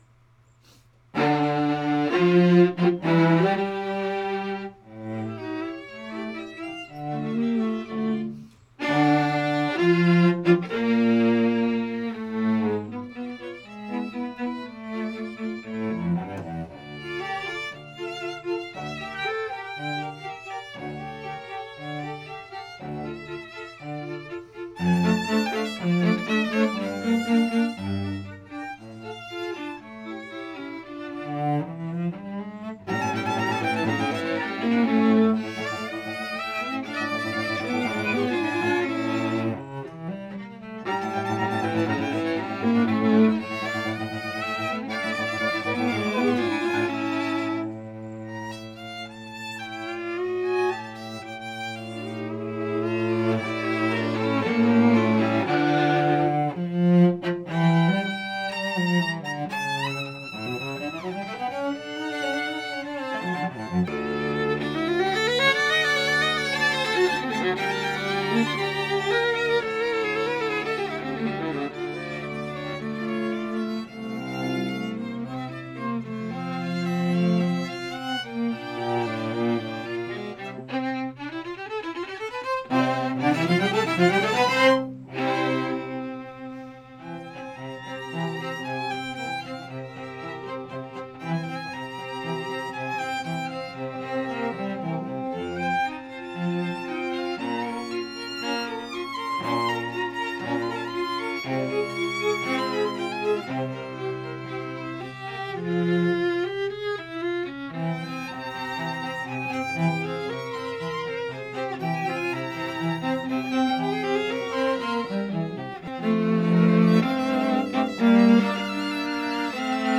2:00 PM on July 20, 2014, "Music with a View"
Allegro